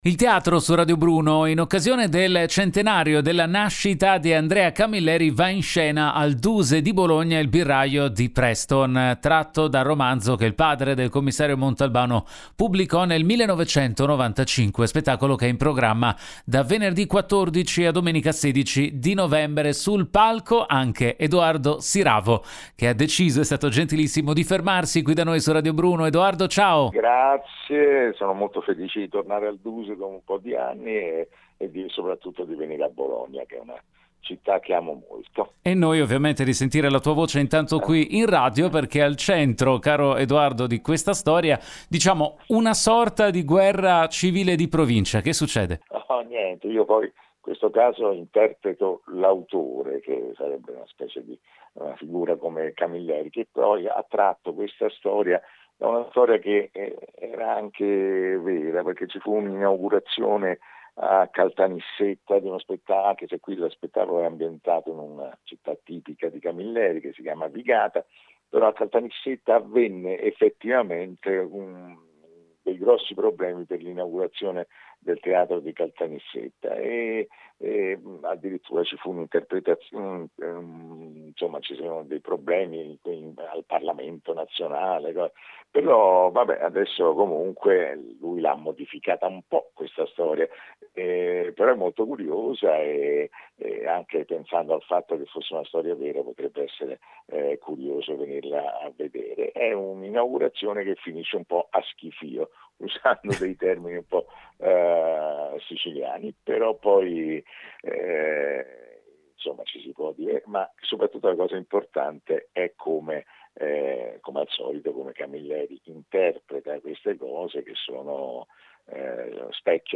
Home Magazine Interviste Edoardo Siravo presenta “Il birraio di Preston”